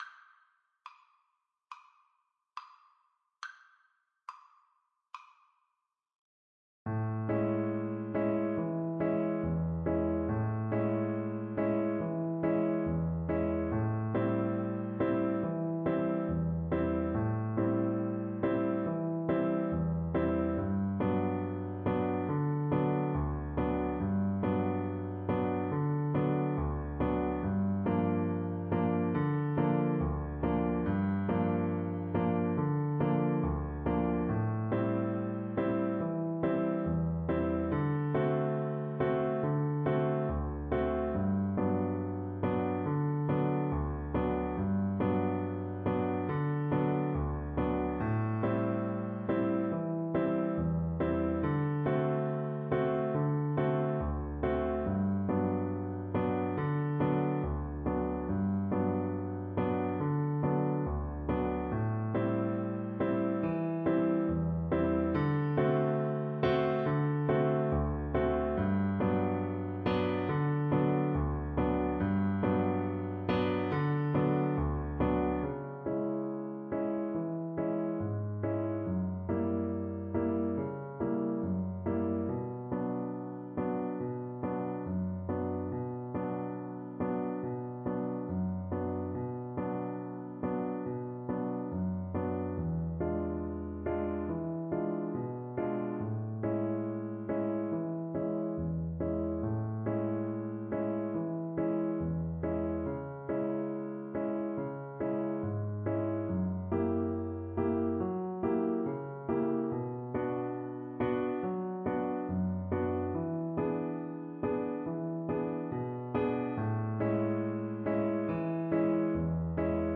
A sultry piece in the rhythm known as 'Beguine'.
4/4 (View more 4/4 Music)
Arrangement for Cello and Piano
World (View more World Cello Music)